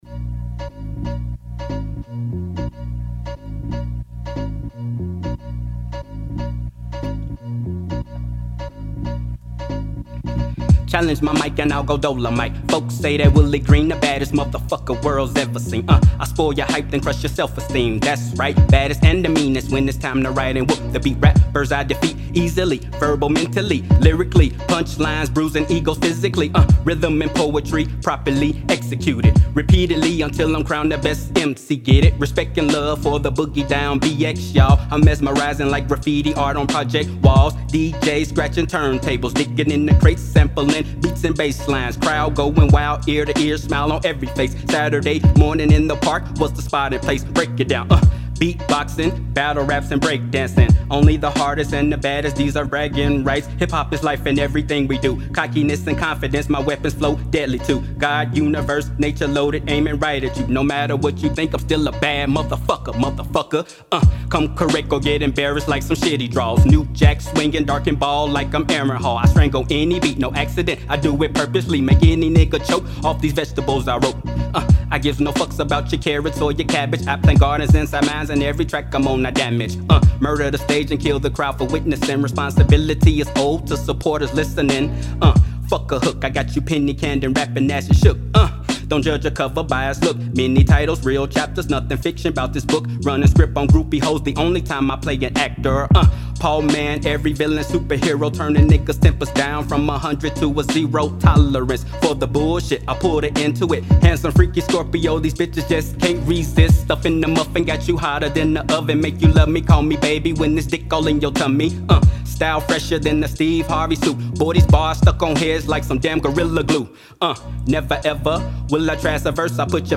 Created 2023-11-28 02:36:01 Hip hop 0 ratings